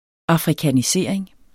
Udtale [ ɑfʁikaniˈseˀɐ̯eŋ ]